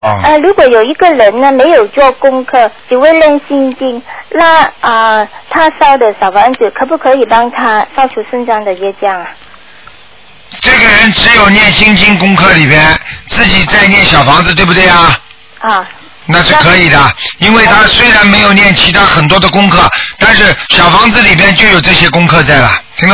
目录：2012年02月_剪辑电台节目录音集锦